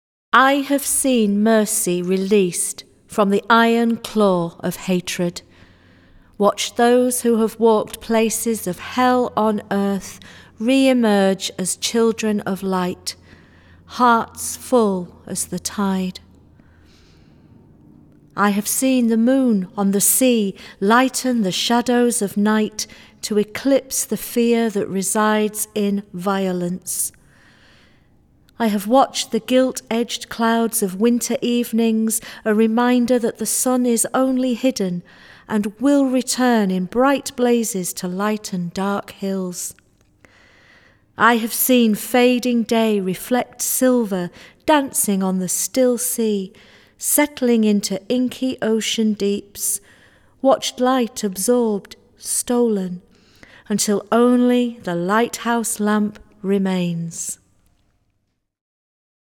Recorded at Craxton Studios, May 12, 2019
Jazz and poetry commemorating the end of The First World War